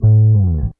BASS 4.wav